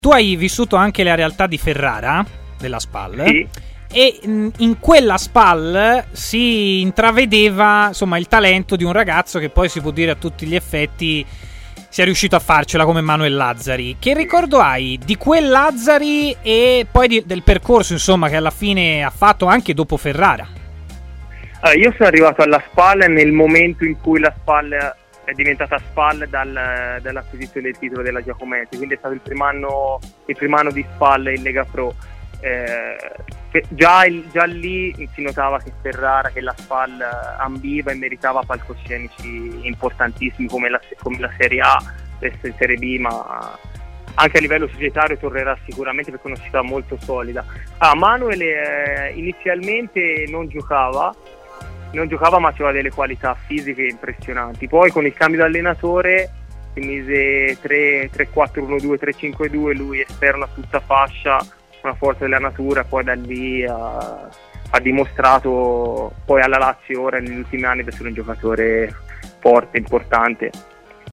Fonte: TMW Radio